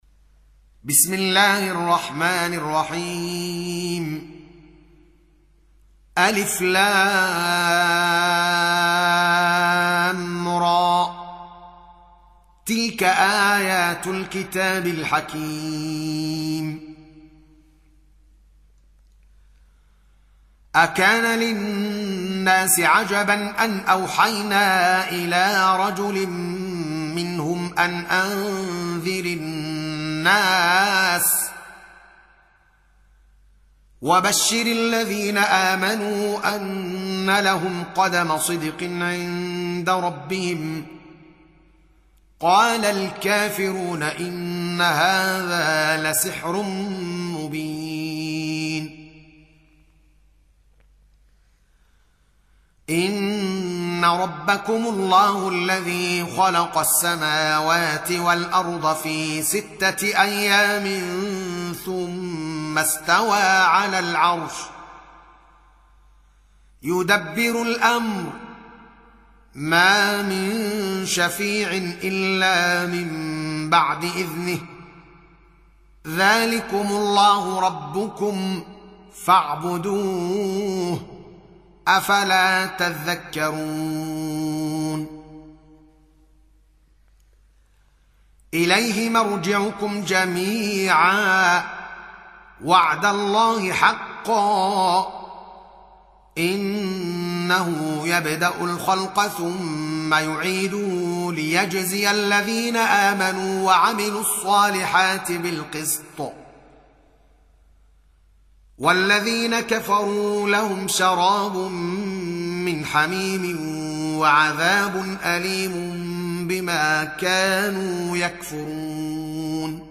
Surah Repeating تكرار السورة Download Surah حمّل السورة Reciting Murattalah Audio for 10. Surah Y�nus سورة يونس N.B *Surah Includes Al-Basmalah Reciters Sequents تتابع التلاوات Reciters Repeats تكرار التلاوات